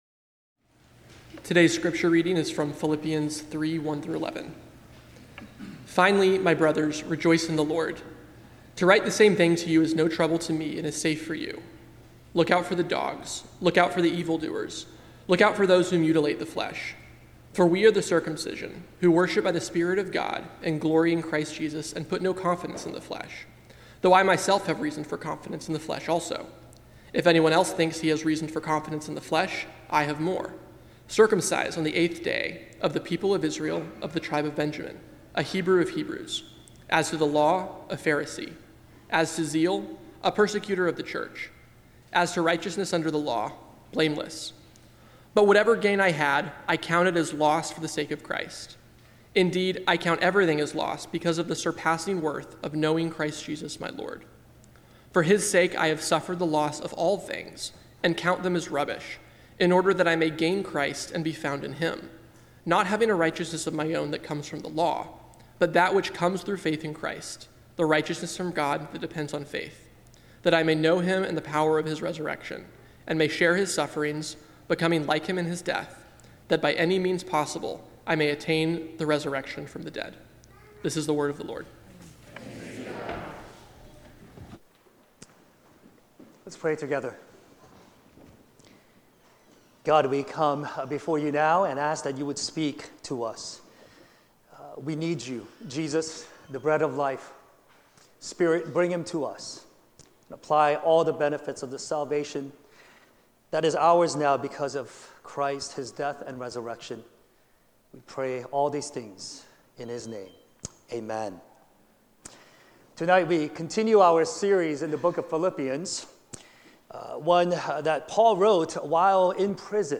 preaches from Philippians 3:1-11.